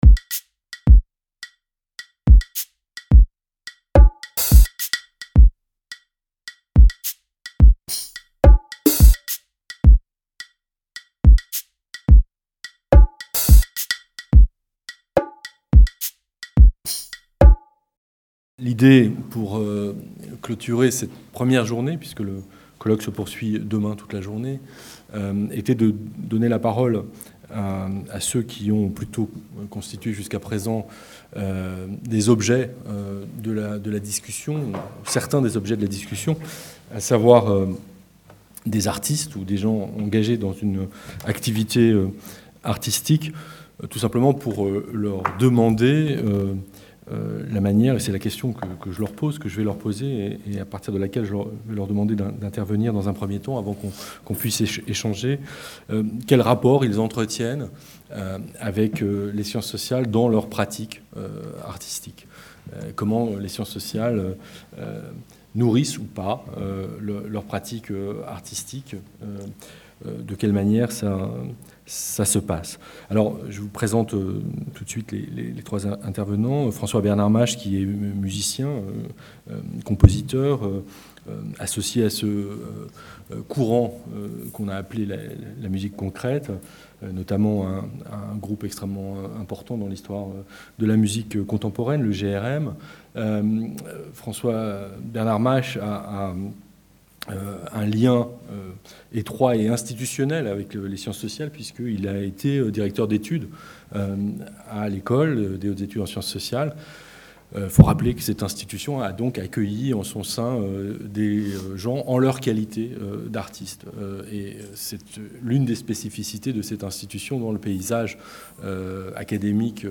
6 - CONDITIONS DE PRODUCTION ET DE CIRCULATION - Table ronde artistes et écrivains | Canal U